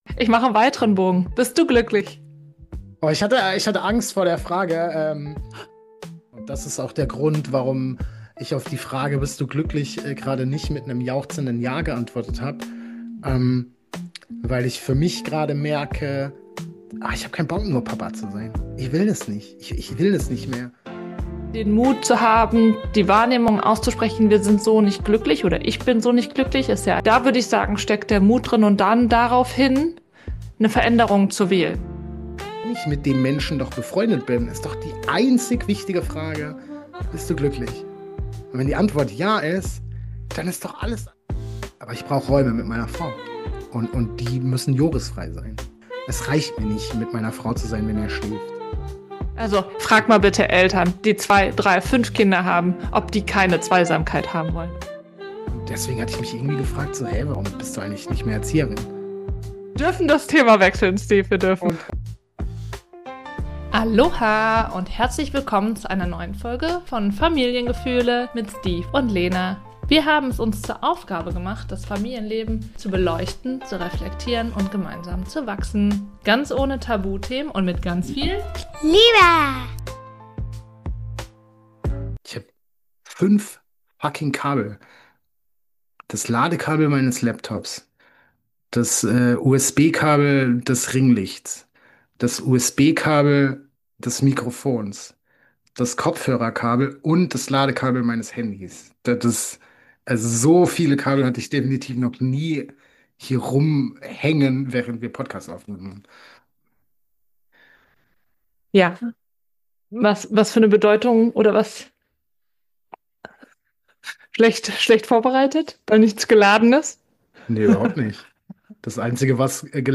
Ehrlich, emotional & inspirierend – wie immer ohne Skript.